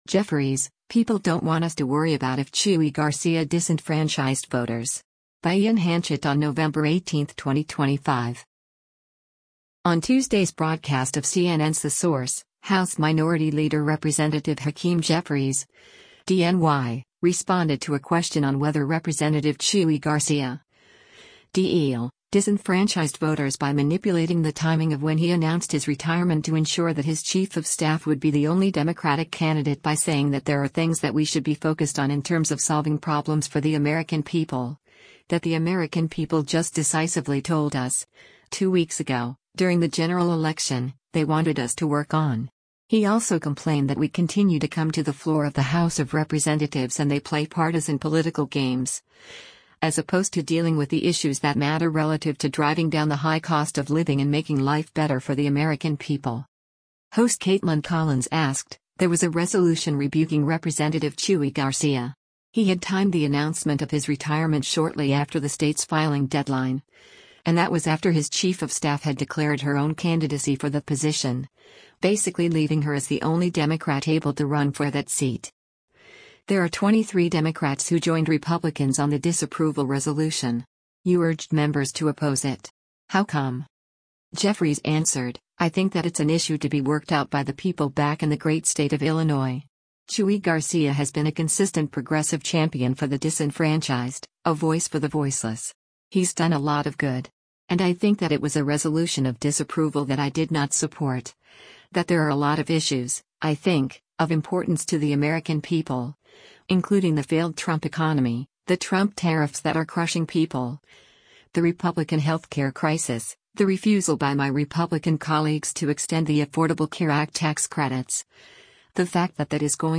Host Kaitlan Collins asked, “There was a resolution rebuking Rep. Chuy Garcia.